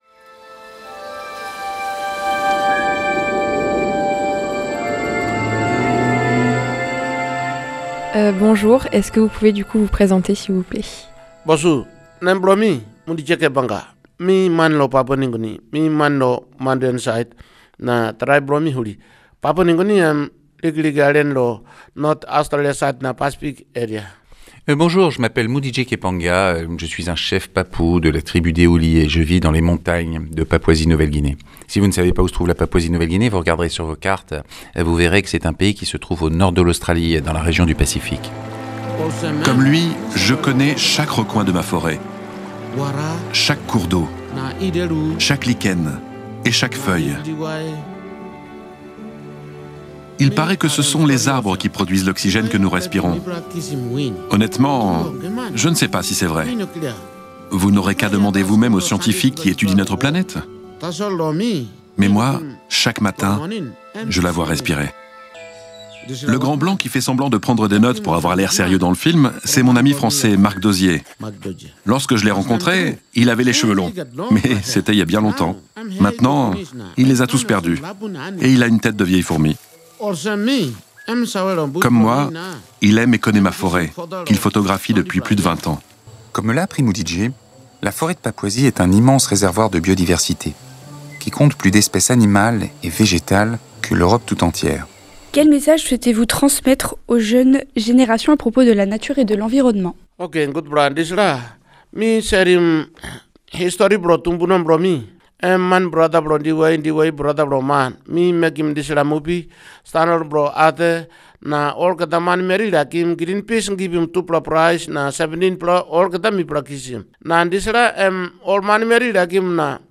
Interview du chef Papou Frères des Arbres